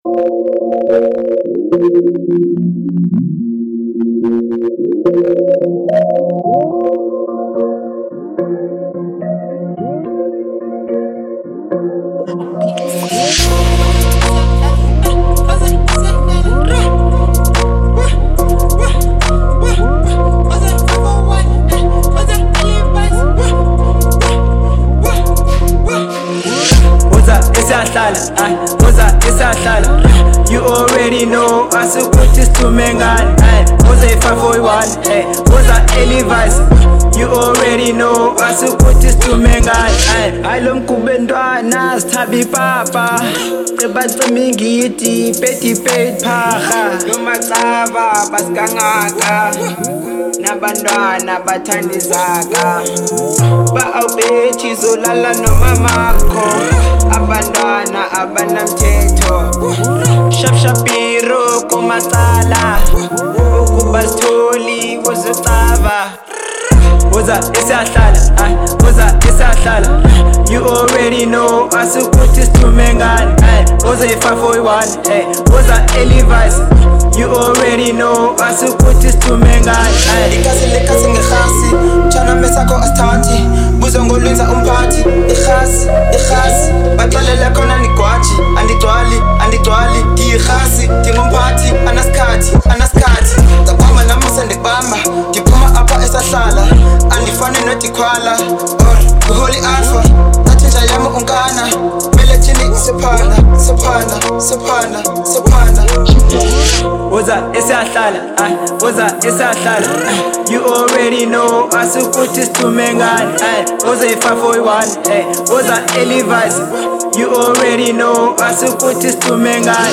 02:28 Genre : Hip Hop Size